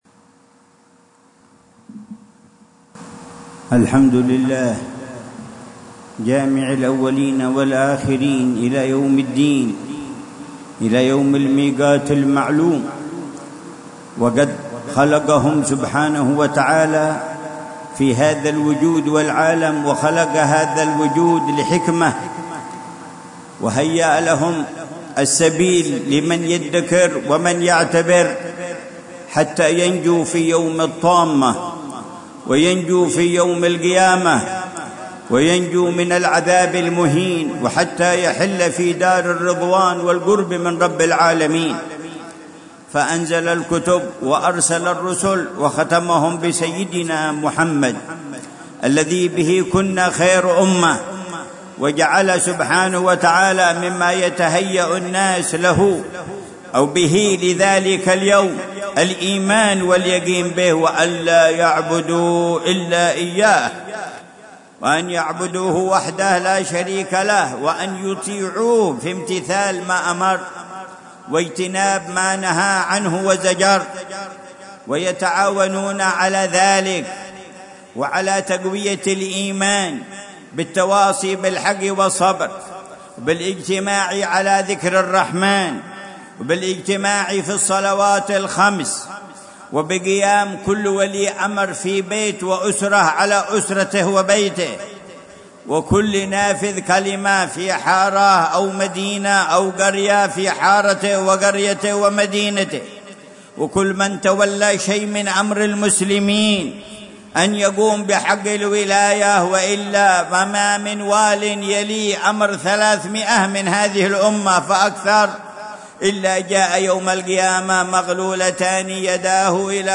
كلمة توجيهية ودعوات مباركة للعلامة الحبيب عمر بن محمد بن حفيظ في مجلس التعريف وقراءة دعاء يوم عرفة، بجوار مسجد مولى خيلة بمدينة تريم، عصر الخميس 9 ذو الحجة 1446هـ بعنوان :